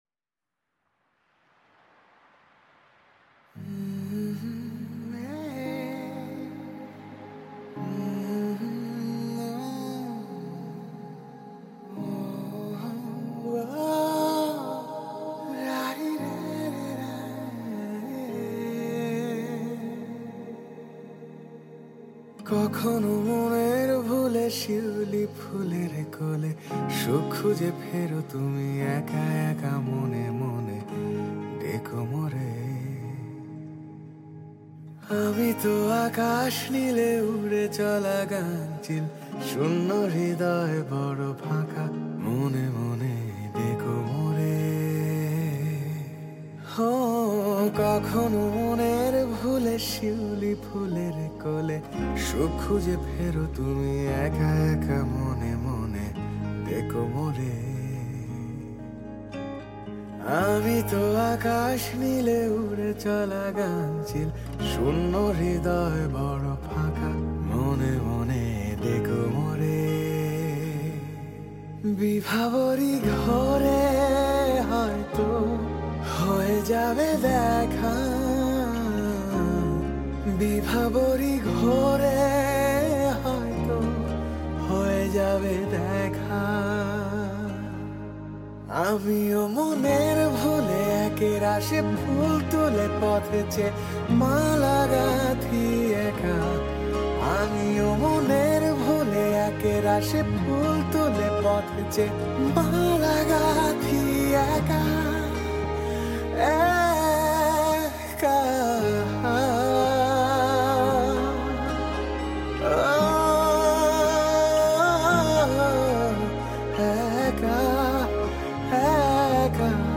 Voice